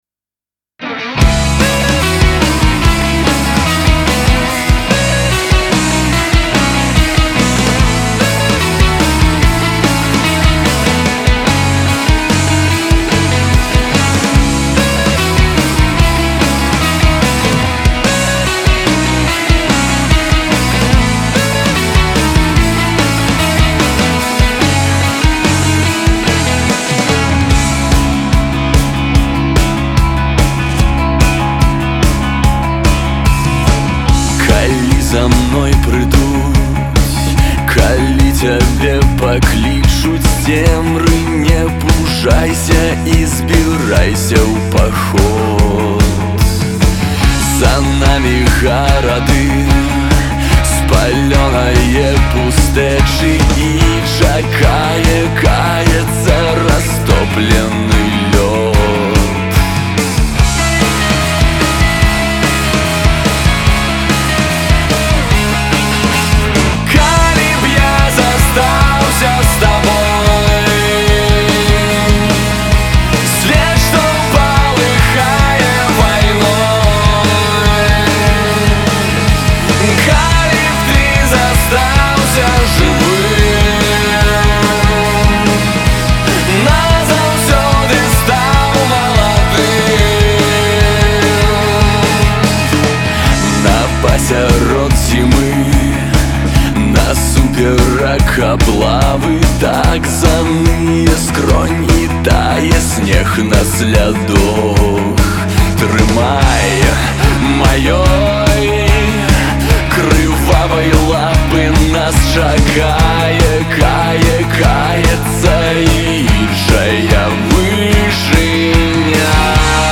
вакал, гітара
бас-гітара